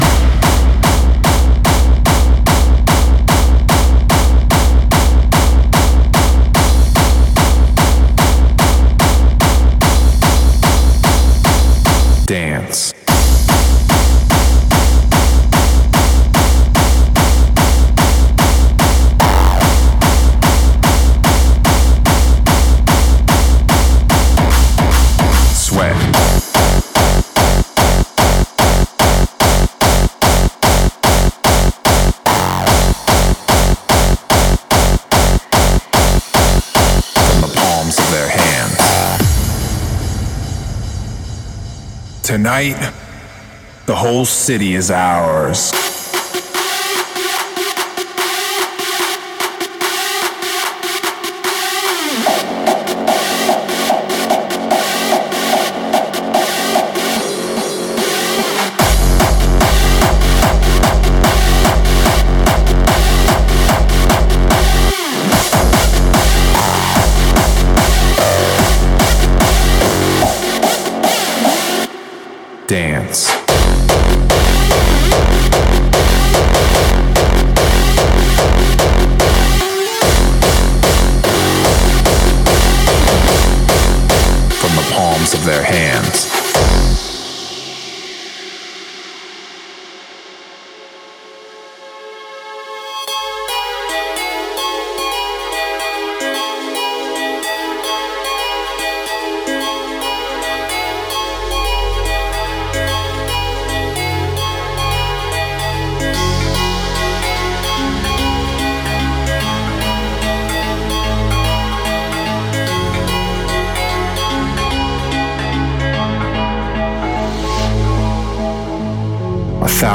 Категория: Shuffle